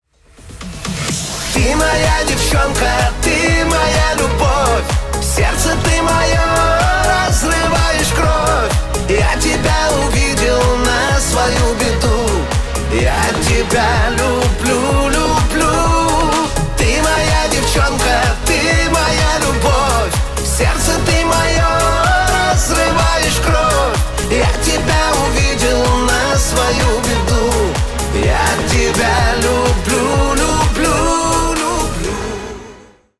Поп Музыка # Шансон